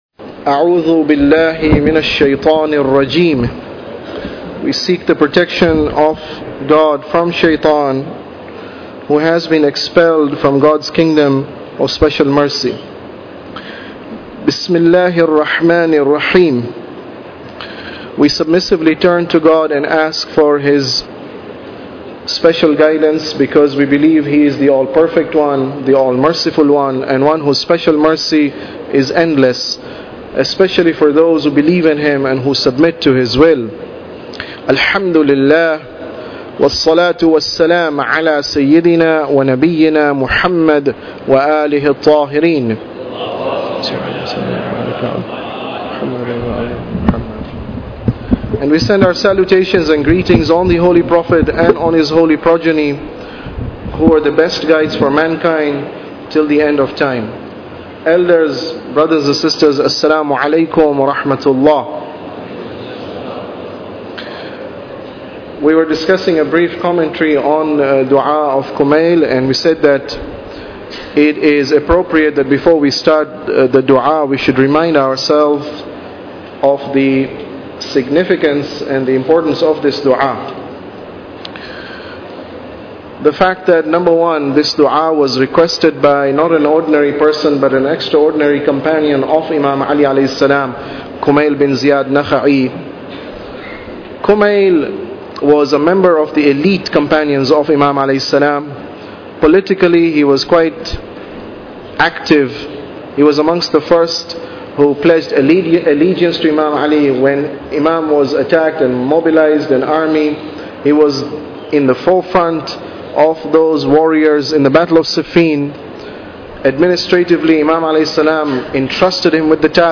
Tafsir Dua Kumail Lecture 8